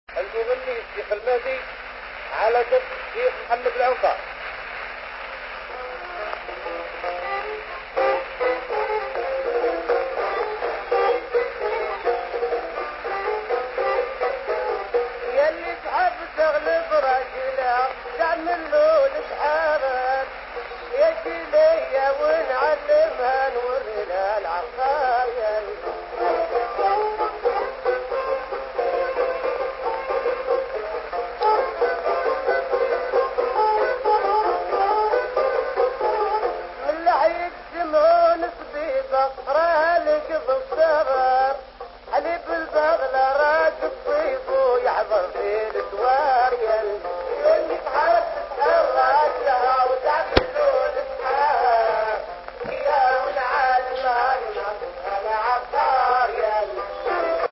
(Au violon El Hadj Mohammed El Anka).
La chanson "ya lli thab taghlab radjalha taamalou lashar" (Pour celle qui veut prendre le dessus sur son mari, voici des recettes de magie blanche). illustration du style musical des années 1930.